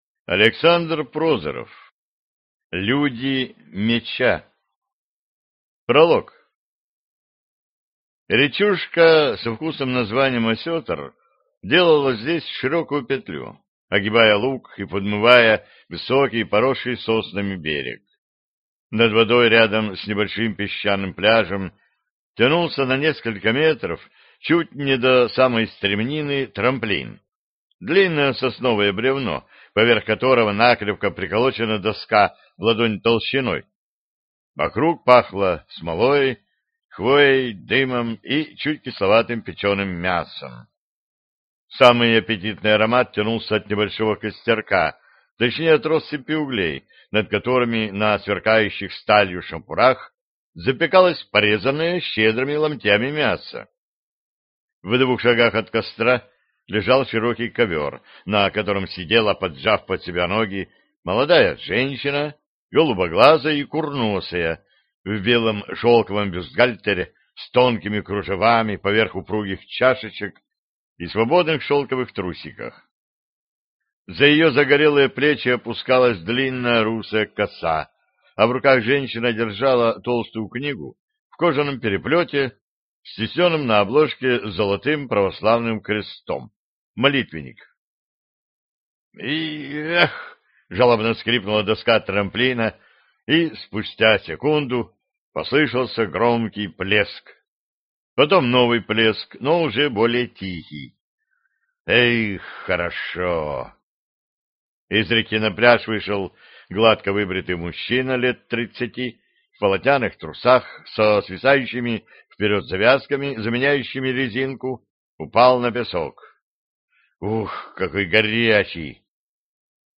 Аудиокнига Люди меча | Библиотека аудиокниг